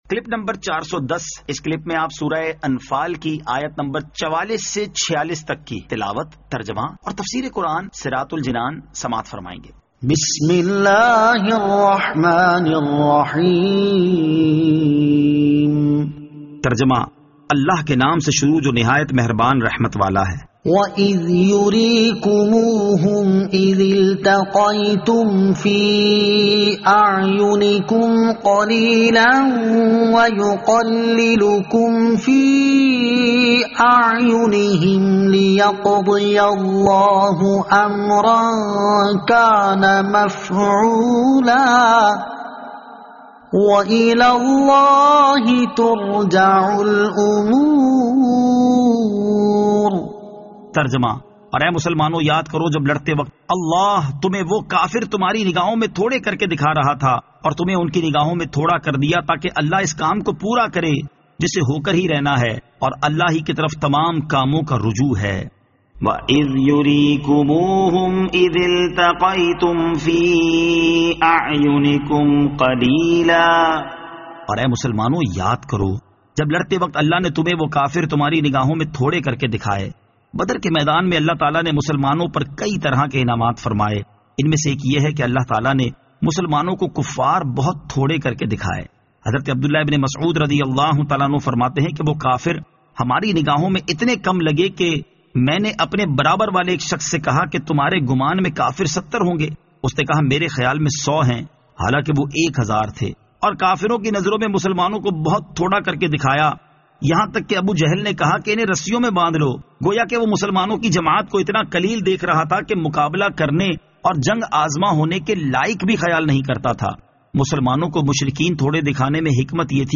Surah Al-Anfal Ayat 44 To 46 Tilawat , Tarjama , Tafseer